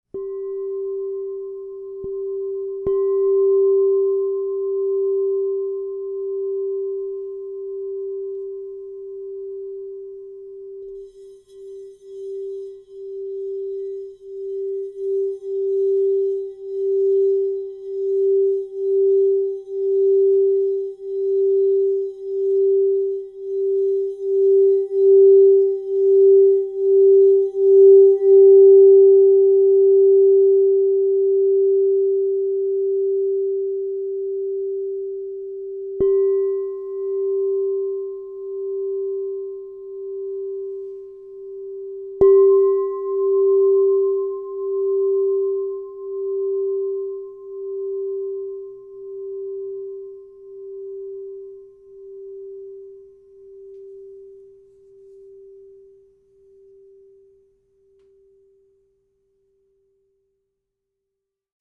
Tesseract Salt, Aqua Aura Gold 6" G -5 Crystal Tones Singing Bowl
This sacred instrument merges the elemental purification of Tesseract Salt with the uplifting vibrational light of Aqua Aura Gold, producing harmonics of deep renewal, illumination, and transformation.
At 6 inches in size, in the note of G -5, this instrument resonates with the throat chakra, empowering expression, sacred sound, and alignment of the inner voice with truth. As a True Tone bowl, it harmonizes beautifully with traditionally tuned instruments, allowing it to sing in unison with other sacred tools and musical companions.
Its compact form makes it ideal for personal meditation, ceremonial work, or intimate healing journeys, offering a voice that is both crystalline and commanding.
432Hz (-), 440Hz (TrueTone)